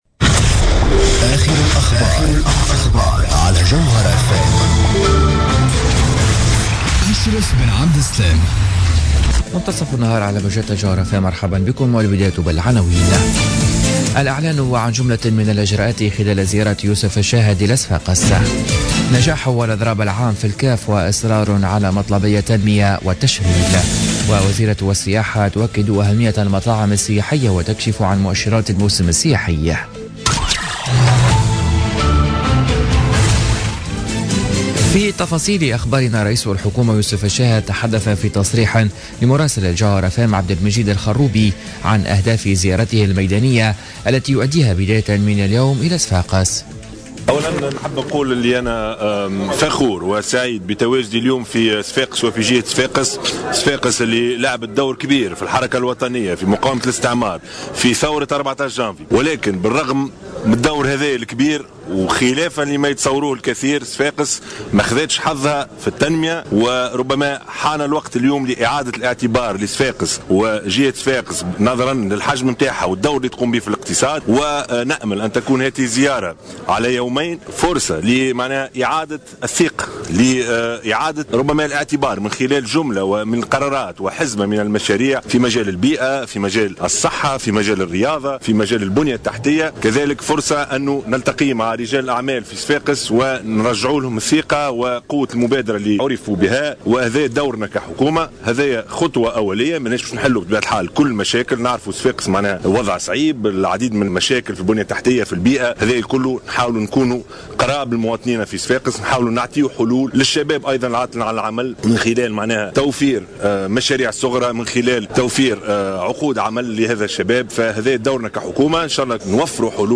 نشرة أخبار منتصف النهار ليوم الخميس 20 أفريل 2017